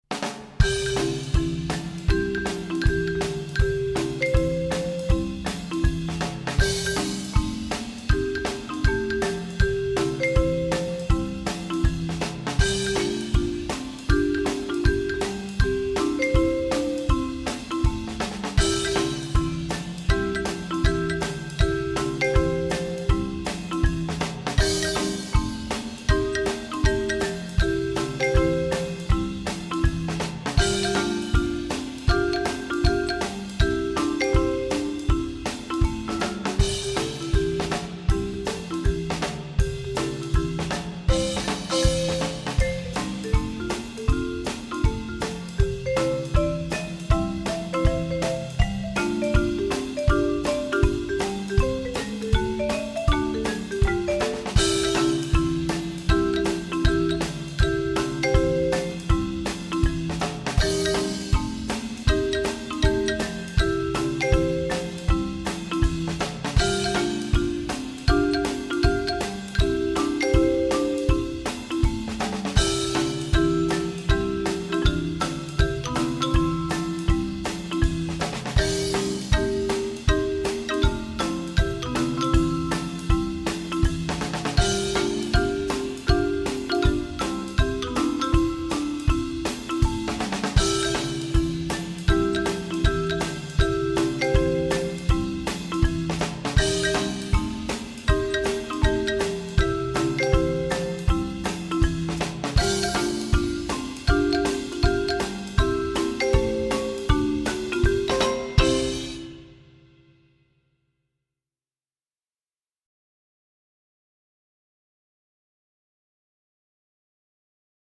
Voicing: Percussion Parts